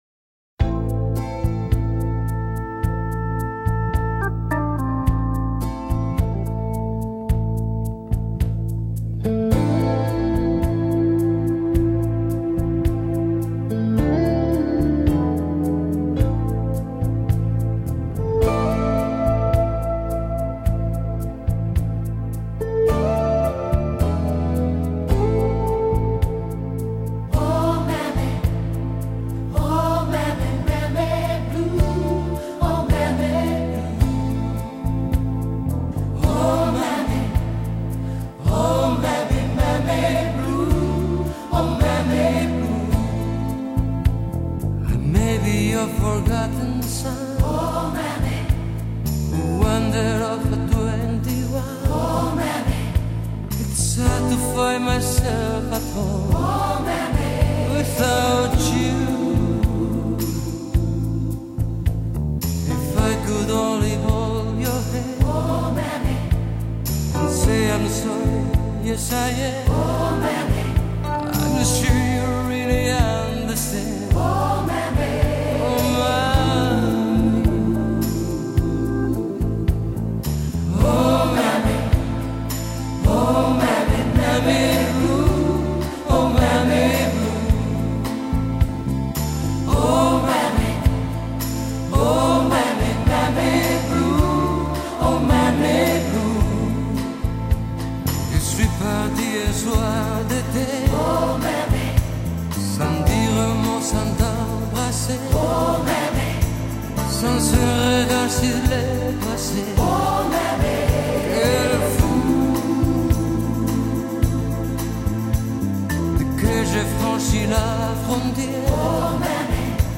与富磁性的嗓音